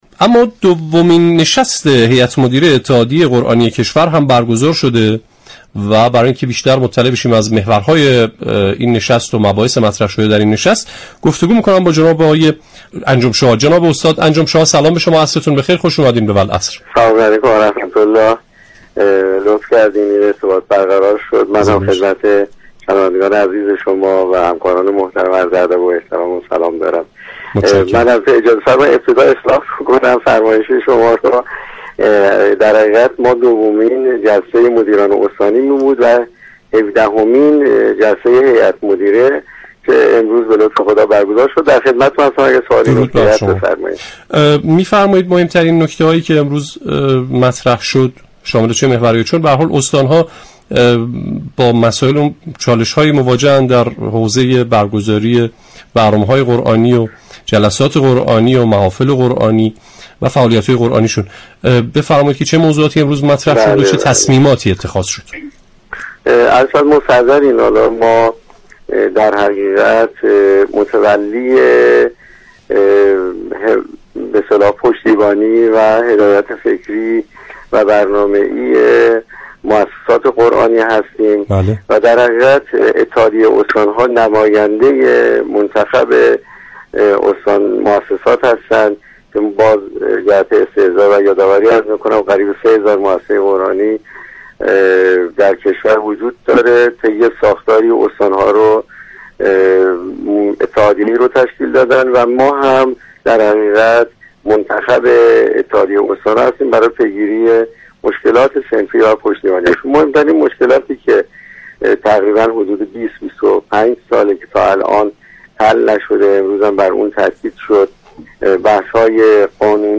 در گفتگو با برنامه والعصر